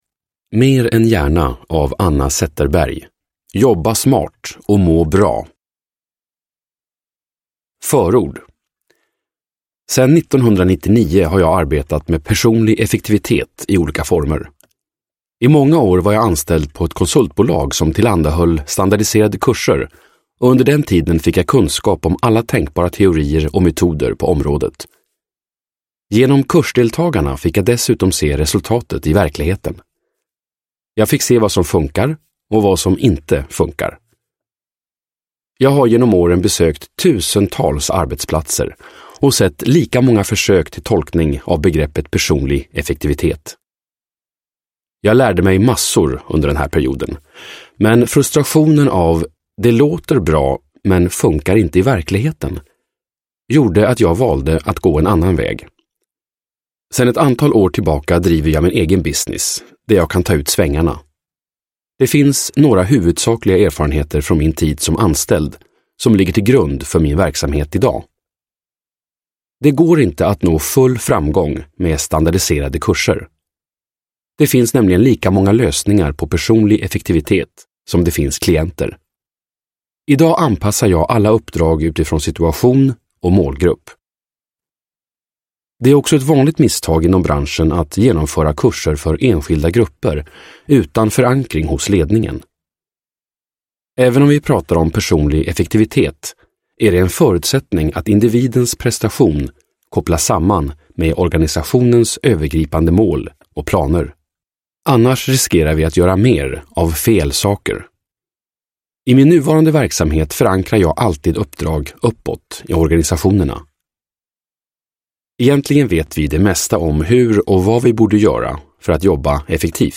Mer än hjärna : jobba smart och må bra – Ljudbok – Laddas ner